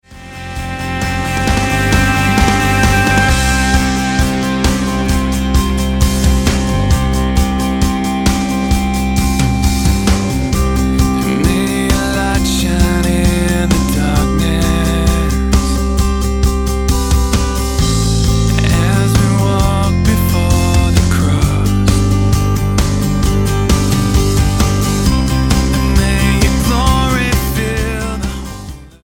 Australian singer, songwriter and worship leader
Style: Pop